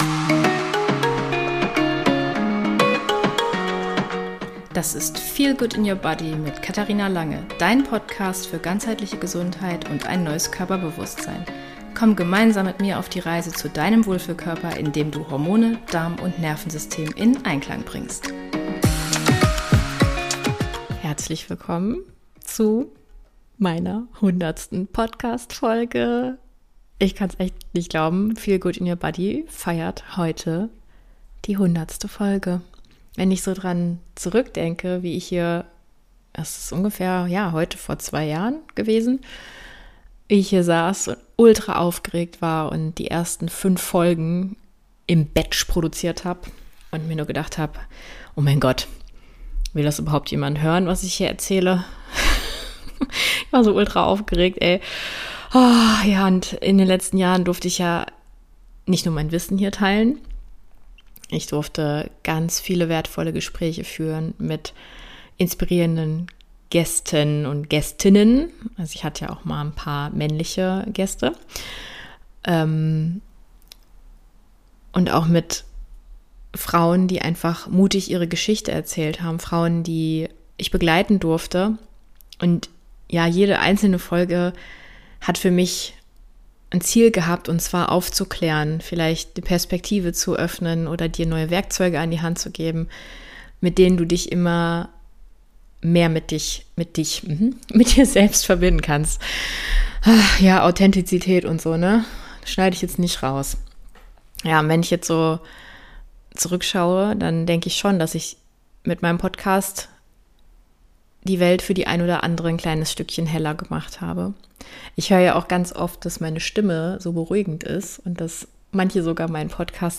Diese Jubiläumsfolge ist kein Rückblick nur aus meiner Sicht – sondern ein Community Q&A. Ich habe eure Fragen gesammelt und beantworte sie in dieser Folge ganz ausführlich.